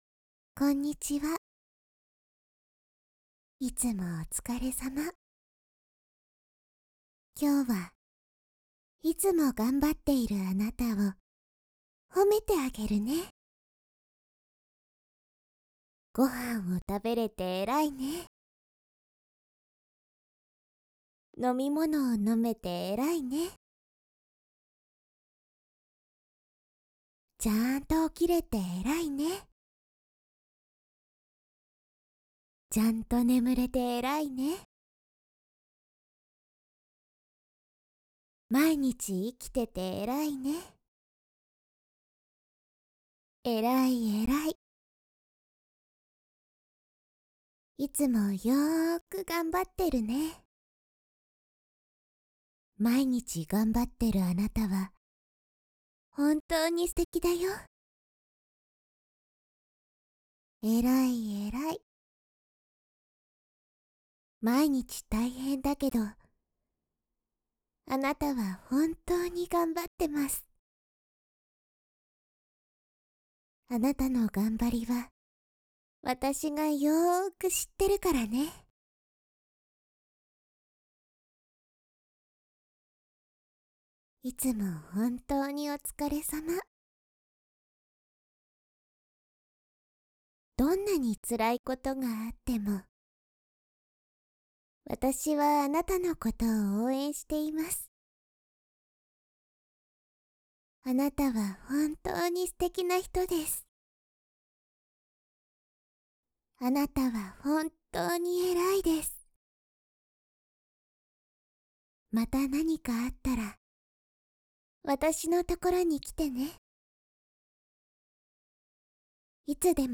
【癒しボイス】
纯爱/甜蜜 日常/生活 温馨 萌 健全 治愈 皆大欢喜 催眠音声